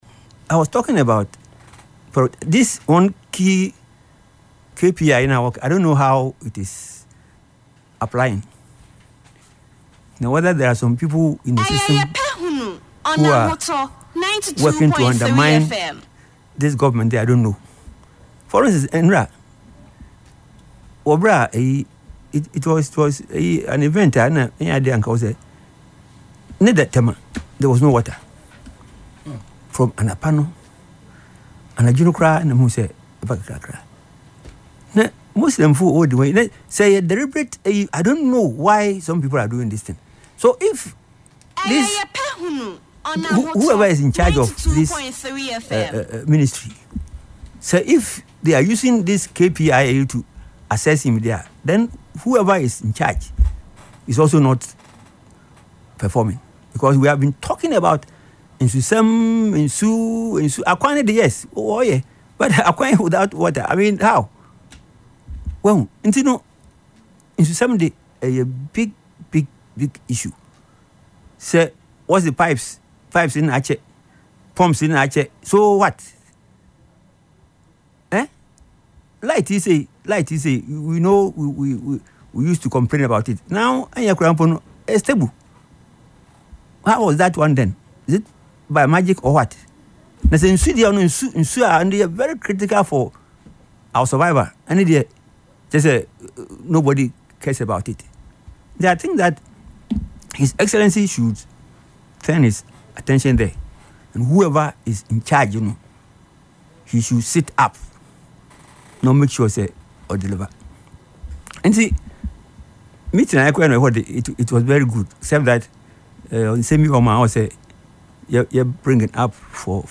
Speaking on Ahotor FM’s Yepe Ahunu programme on Saturday, March 21, he stressed that based on Key Performance Indicators (KPIs), officials responsible for ensuring a steady flow of water must be held accountable for the situation.